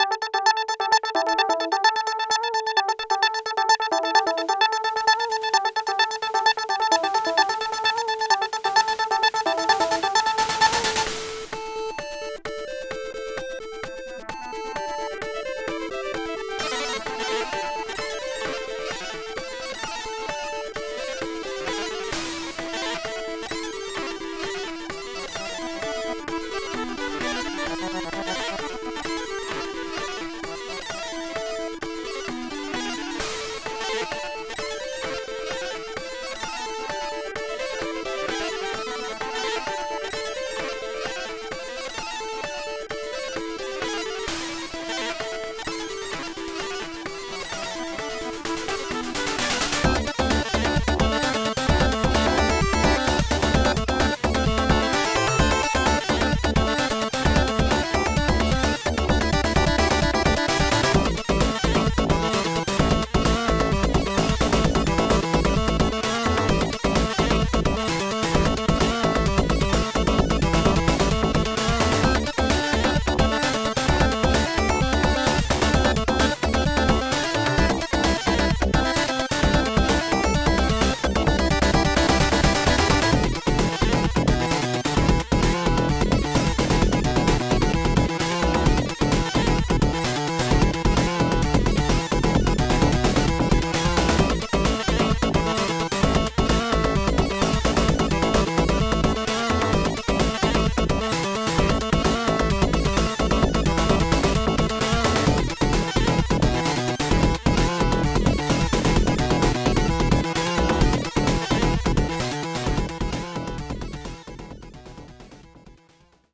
Level 1 music OPL3